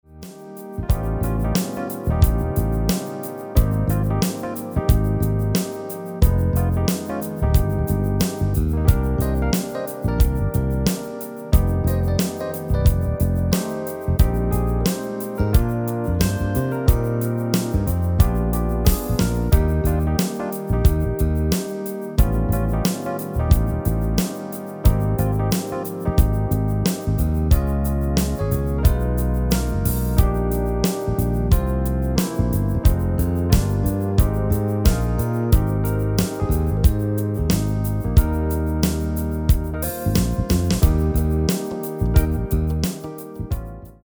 Demo/Koop midifile
Genre: R&B / Soul / Funk
- GM = General Midi level 1
- Géén vocal harmony tracks
Demo = Demo midifile